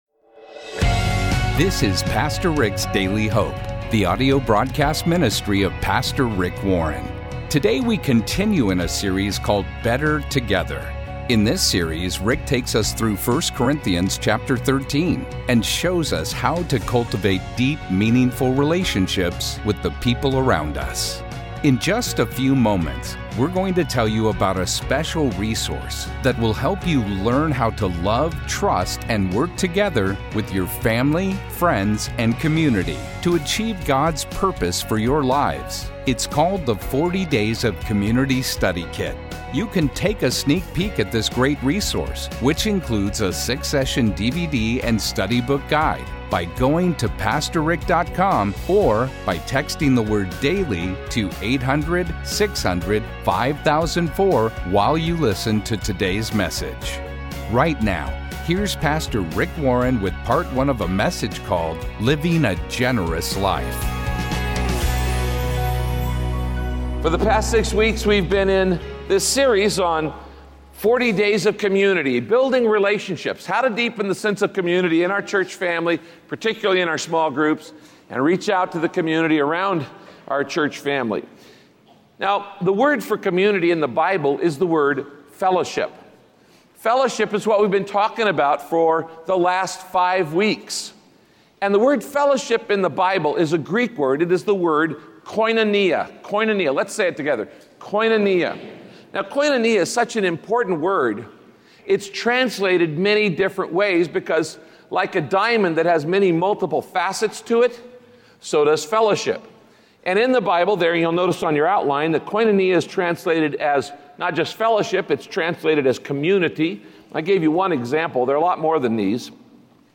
My Sentiment & Notes Living a Generous Life - Part 1 Podcast: Pastor Rick's Daily Hope Published On: Mon Aug 14 2023 Description: The Bible says that your heart will be wherever you put your time, money, and energy. Listen to Pastor Rick as he teaches that generosity isn't just about giving money, but also about investing time and energy into building relationships with those around you.